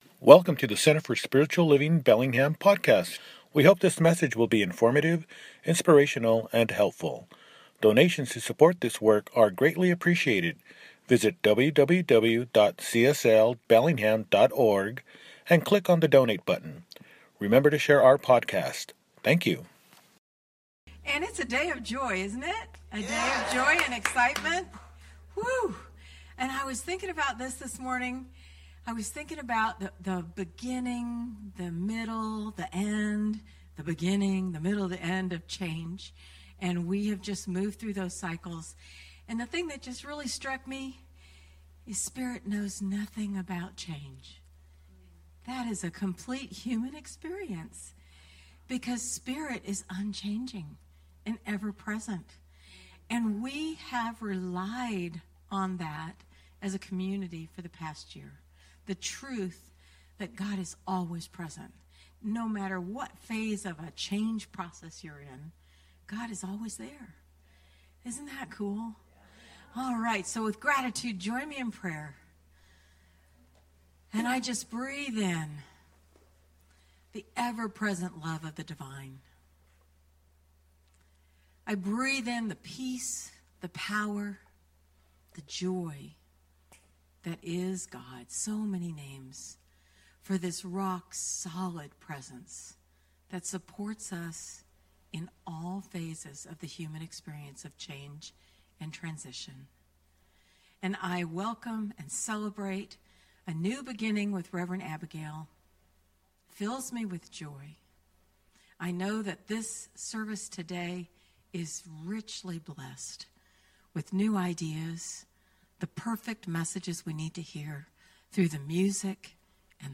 Abundance Consciousness – Celebration Service